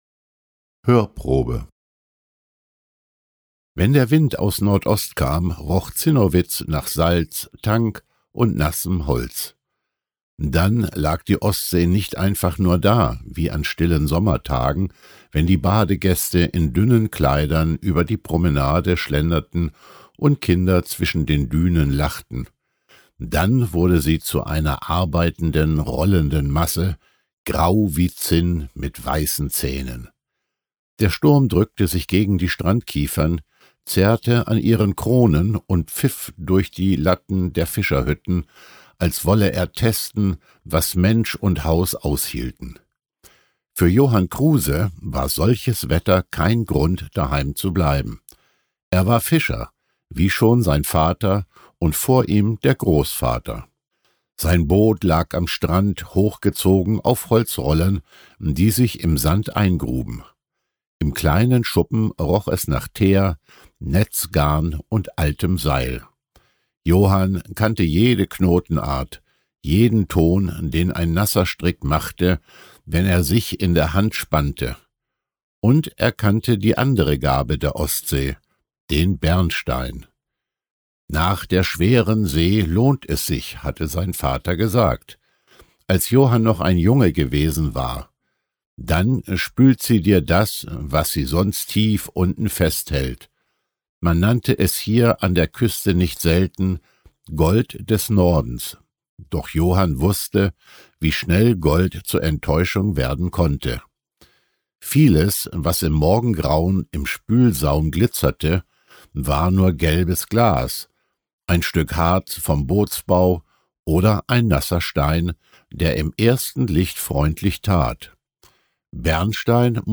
Besonders überzeugend ist die ruhige Erzählweise.
Ein stilles, atmosphärisches Hörerlebnis – wie ein Blick über weite Felder unter einem offenen Himmel.
Heimatgeschichten-aus-Pommern_Hoerprobe.mp3